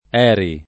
vai all'elenco alfabetico delle voci ingrandisci il carattere 100% rimpicciolisci il carattere stampa invia tramite posta elettronica codividi su Facebook ERI [ $ ri ] n. pr. f. — sigla di Edizioni Radio Italiana, oggi intesa come sigla di Edizioni RAI